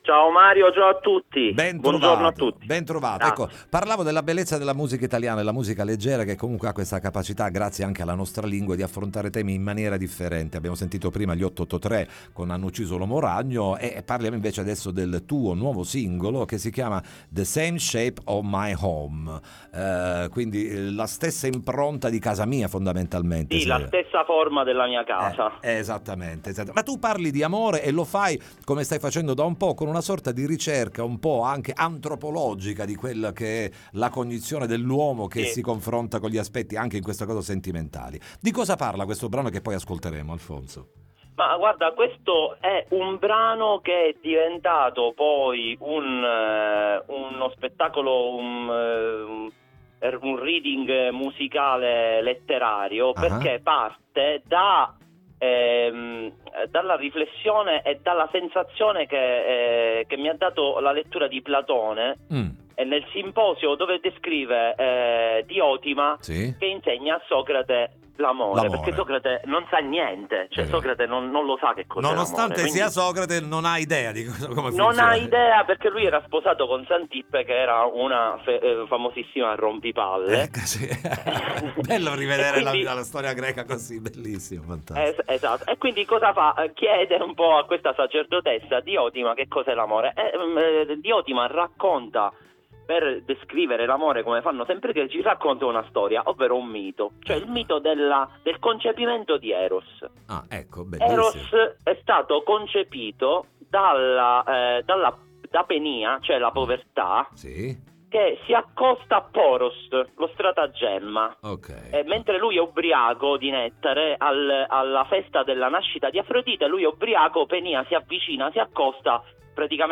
ne parliamo con lui in collegamento telefonico